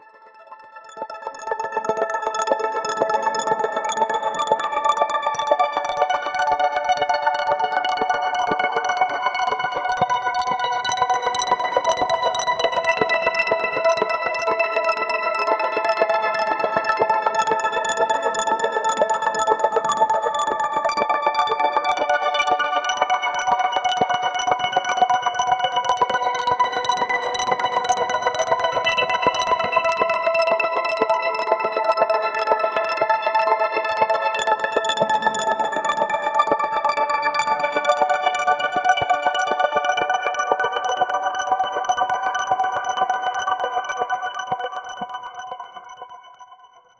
minimal-ambient-sci-fi-tr-gznkpgmg.wav